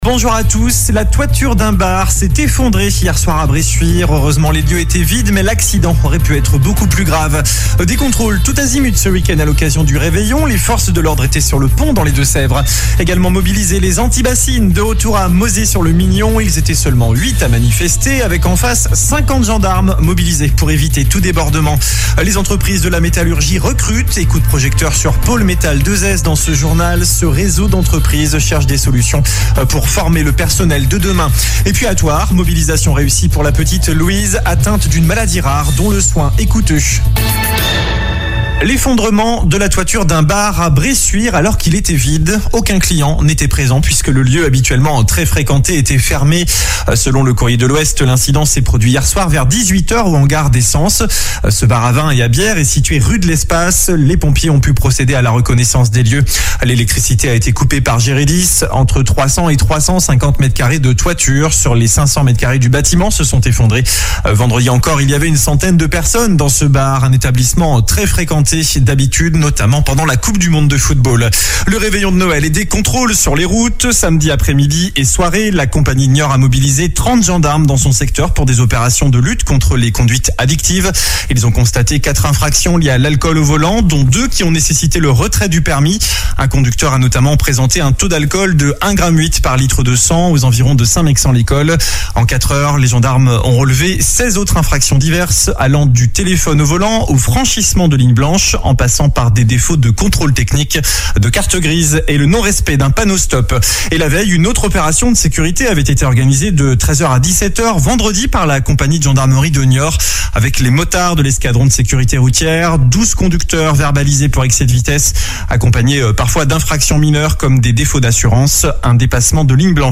Journal du lundi 26 décembre